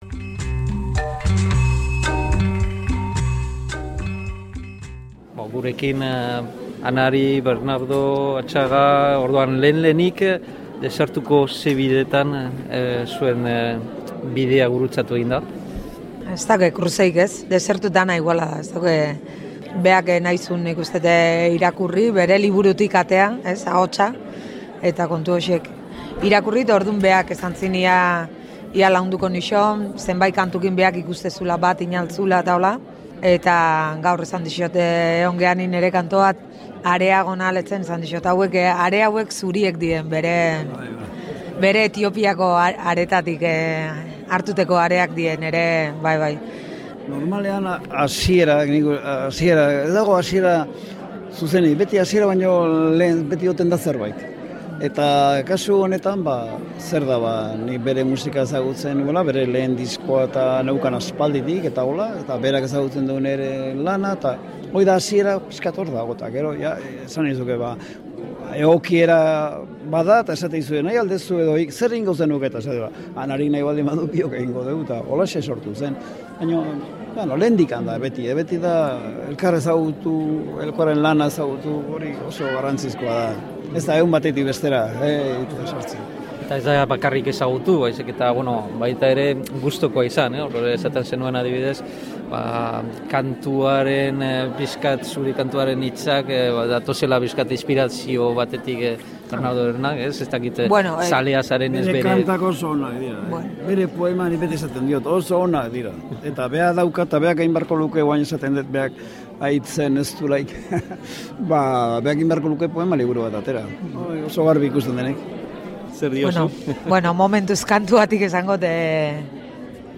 Literaturaren eta musikaren bategitea hartu dugu hizpide gaurko elkarrizketaren tartean. Bernardo Atxaga idazlea eta Anari abeslaria batera azaltzen diren emanaldi baten ingurukoak ekarri ditugu, Desertuko biztanleei buruz errezitaldiaren ingurukoak.
Joan den otsailaren 28an, Baigorriko Etxauzia gazteluan eskaini zuten ikuskizuna, eta hori aprobetxatuta, Radiokulturako lagunek bi artistekin hitz egin dute.